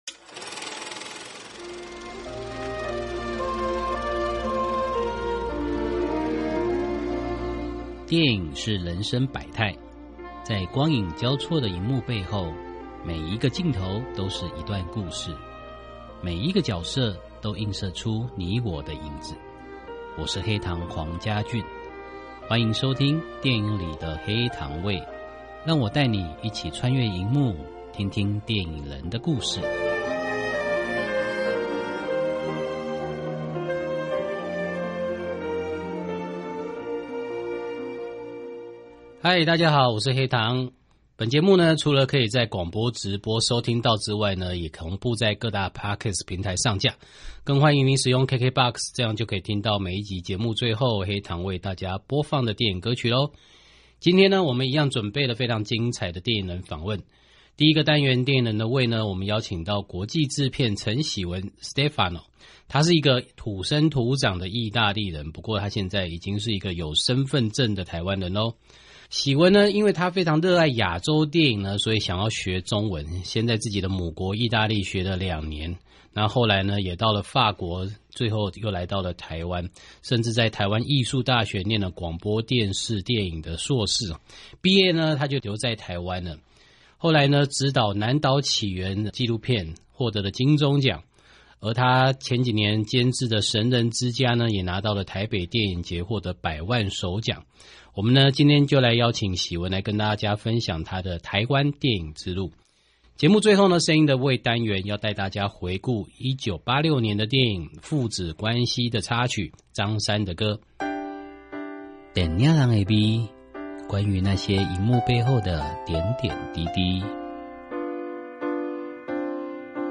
訪問大綱： 1.